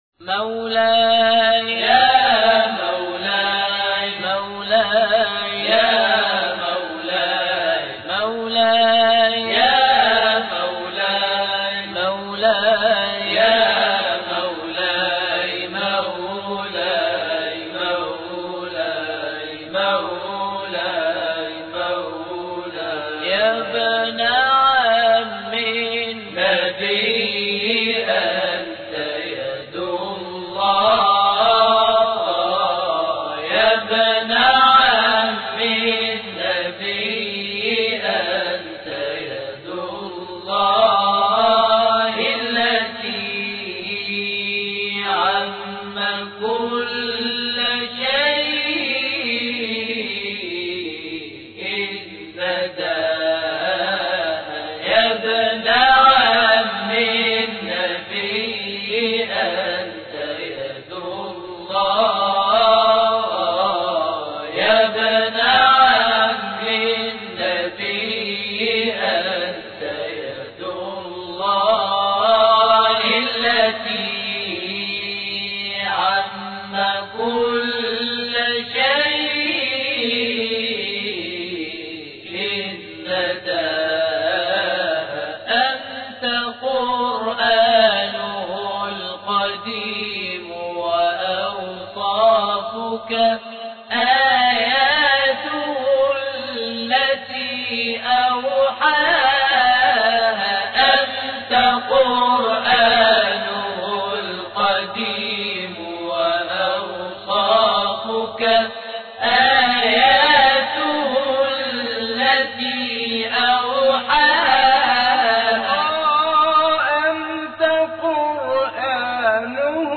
مولاي يا مولاي - مولد الإمام علي (ع) - لحفظ الملف في مجلد خاص اضغط بالزر الأيمن هنا ثم اختر (حفظ الهدف باسم - Save Target As) واختر المكان المناسب